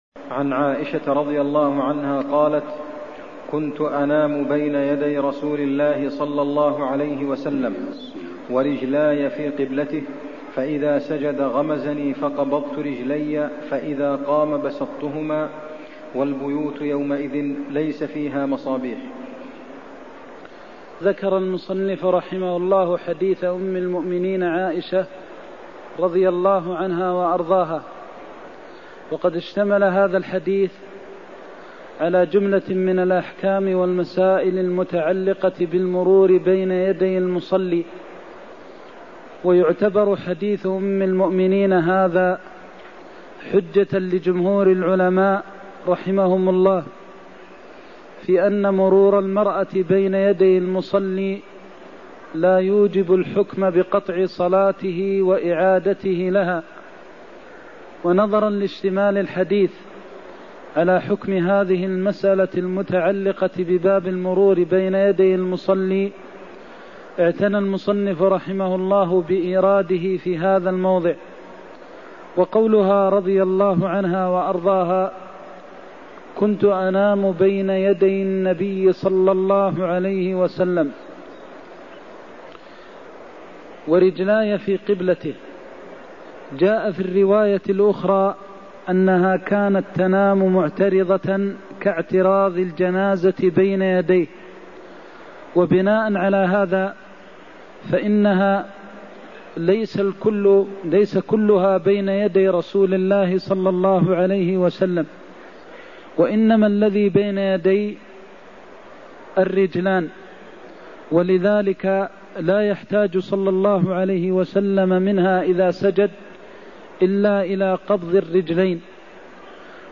المكان: المسجد النبوي الشيخ: فضيلة الشيخ د. محمد بن محمد المختار فضيلة الشيخ د. محمد بن محمد المختار الاعتراض بين يدي المصلي (105)